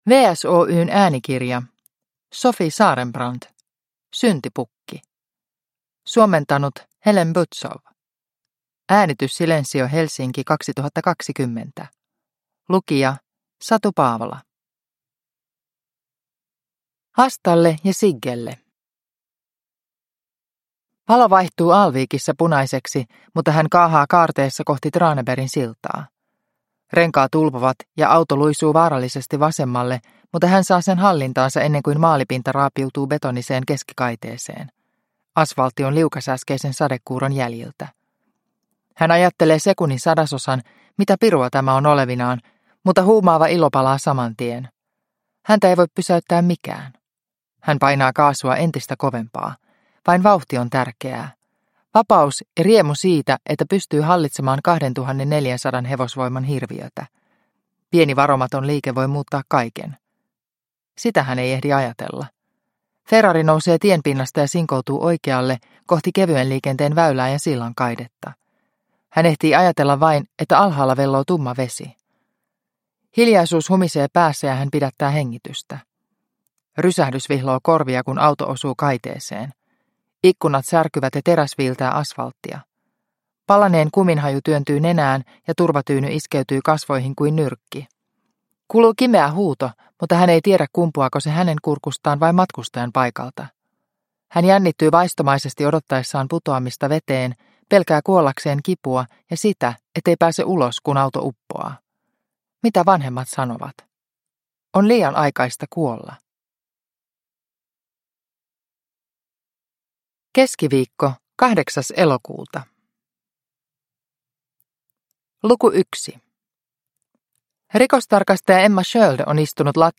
Syntipukki – Ljudbok – Laddas ner